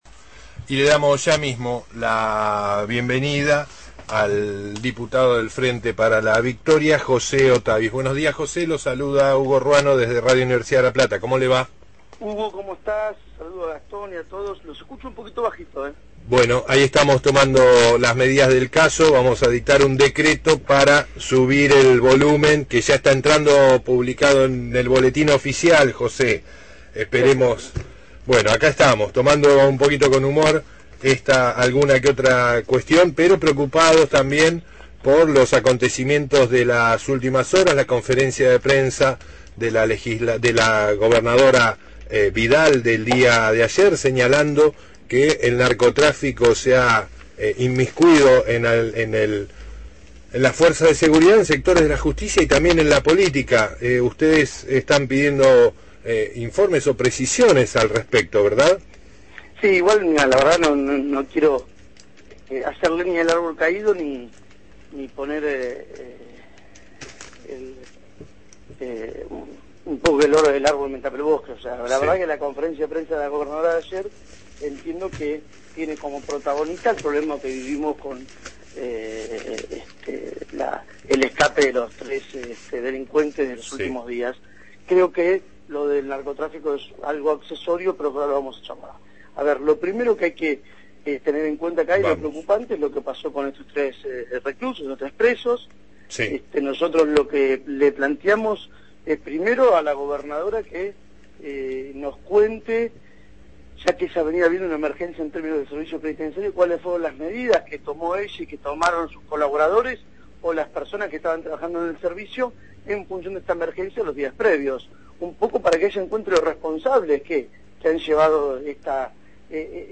José Ottavis, diputado provincial y Presidente del Bloque del Frente para la Victoria, dialogó esta mañana